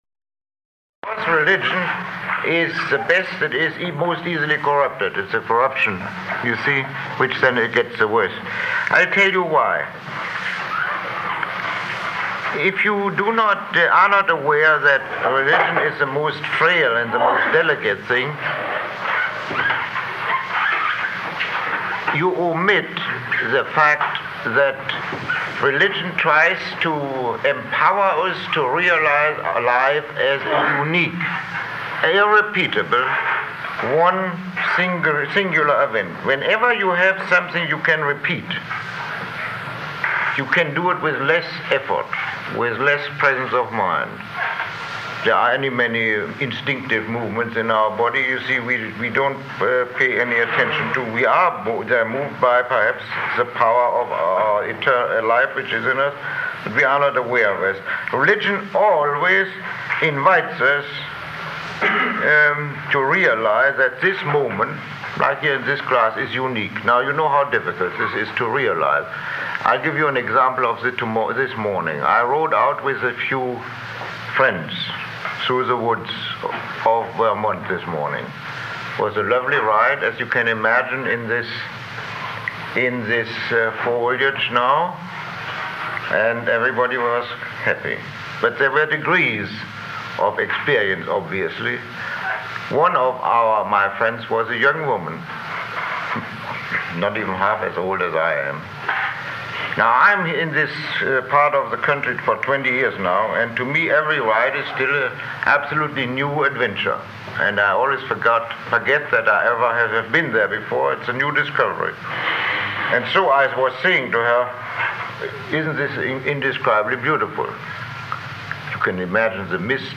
Lecture 03